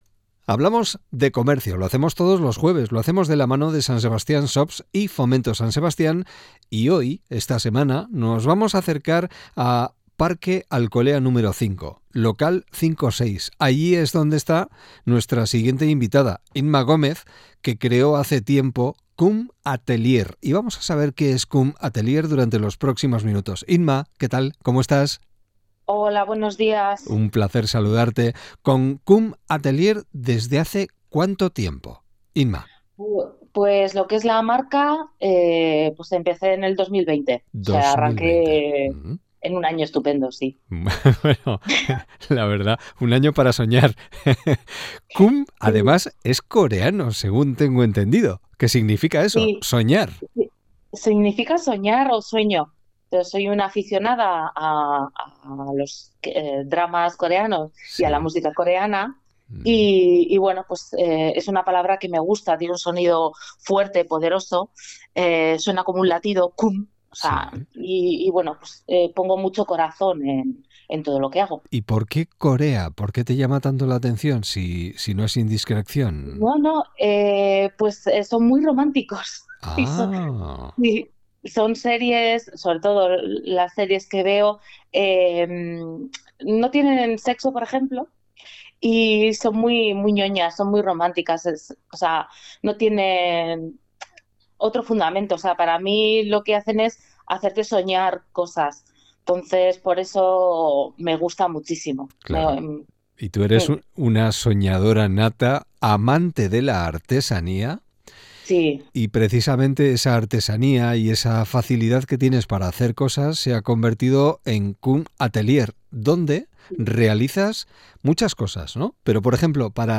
Elkarrizketa osoa: